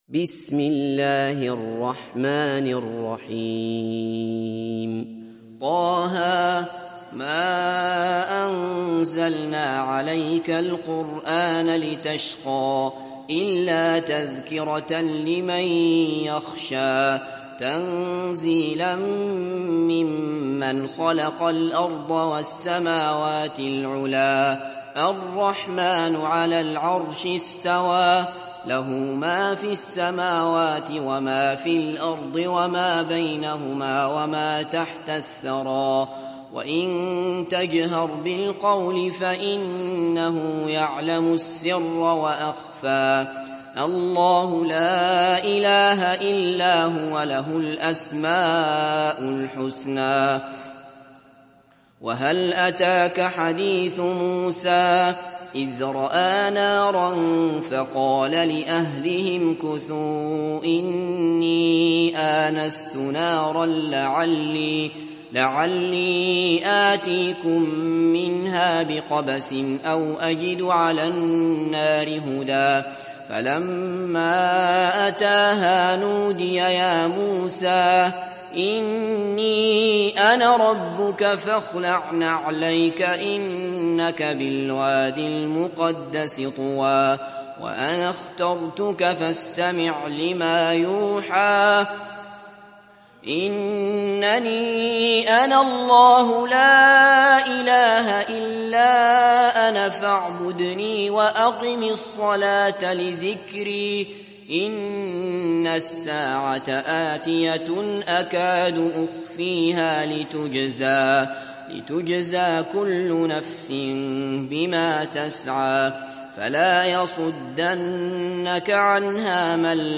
دانلود سوره طه mp3 عبد الله بصفر روایت حفص از عاصم, قرآن را دانلود کنید و گوش کن mp3 ، لینک مستقیم کامل